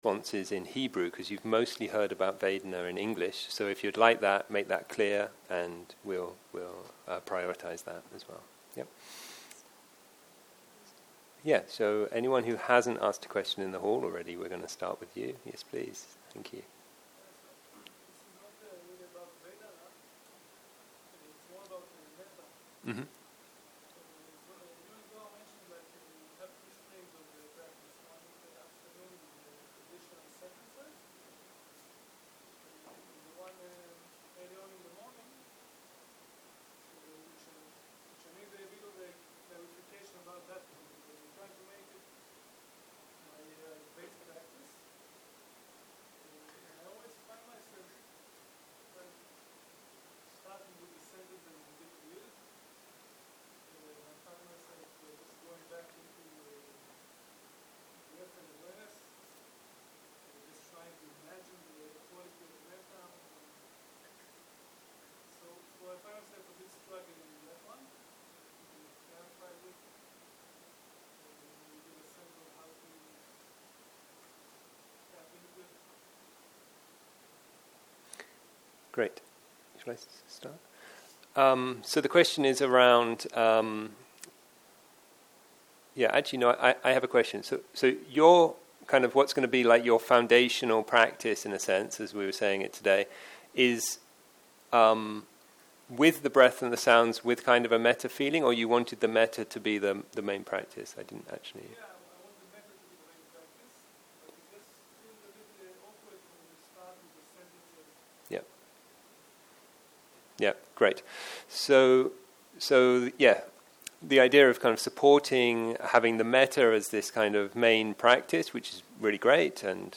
בוקר - שאלות ותשובות
סוג ההקלטה: שאלות ותשובות